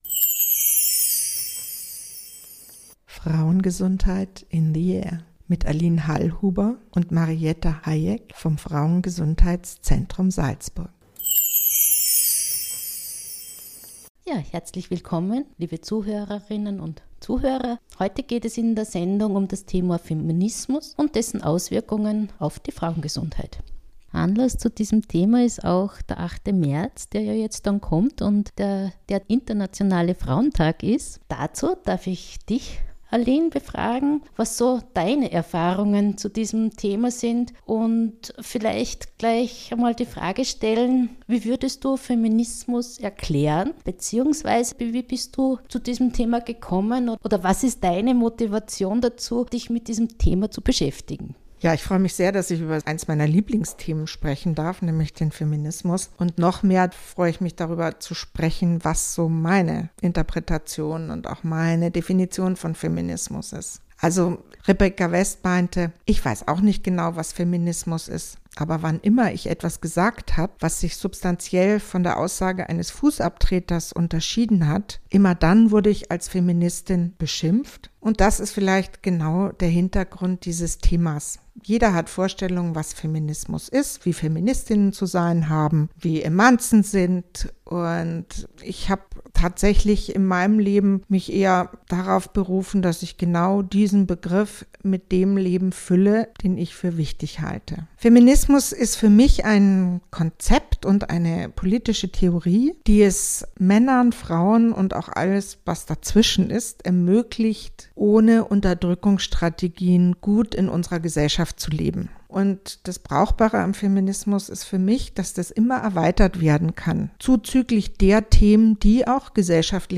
Eine Feministin spricht über Erfahrungen, Erweckungen, Erkenntnisse, die ihre Arbeit im FrauenGesundheitsZentr...